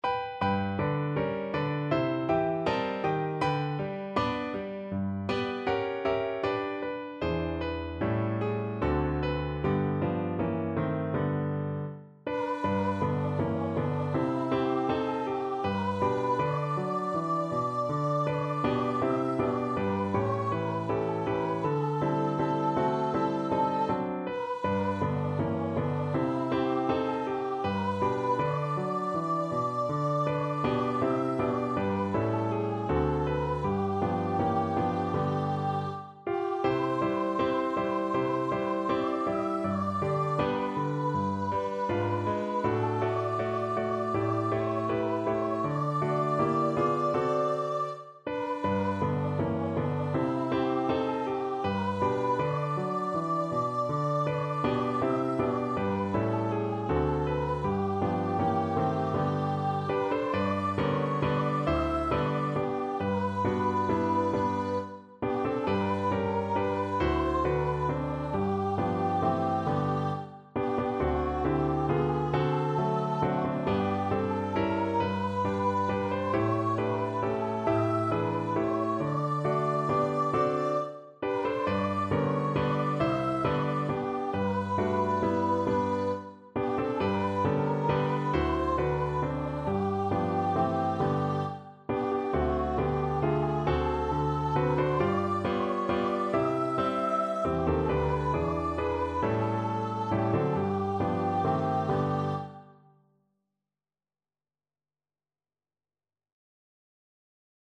Andante
4/4 (View more 4/4 Music)
Pop (View more Pop Voice Music)